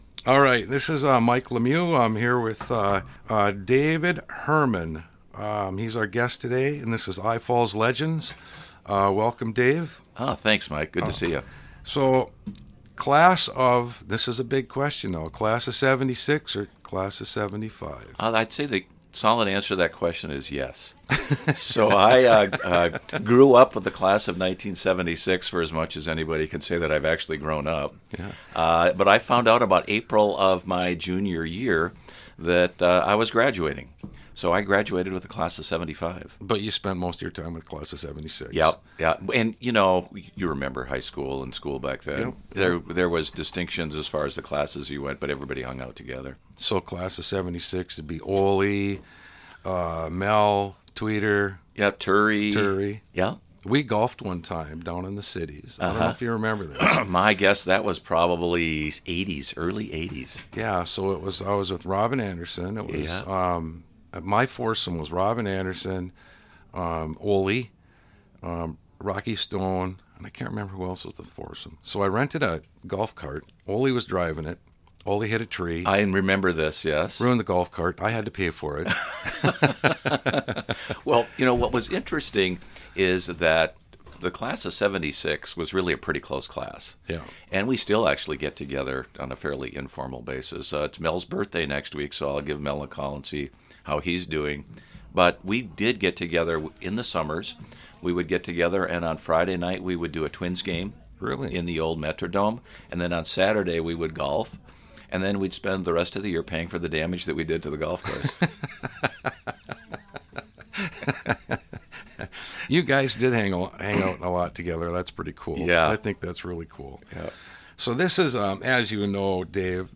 Past Legends Interviews
legends_interview_edit+(1).mp3